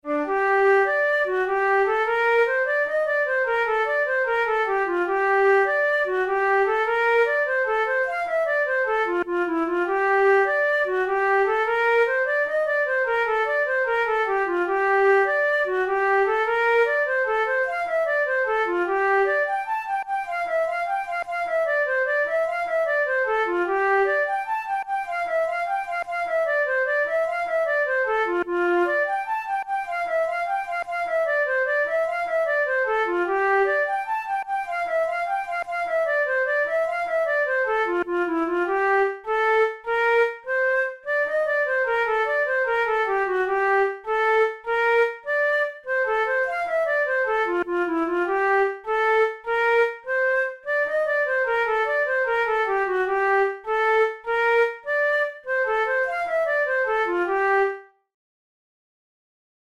InstrumentationFlute solo
KeyG minor
Time signature6/8
Tempo100 BPM
Jigs, Traditional/Folk
Traditional Irish jig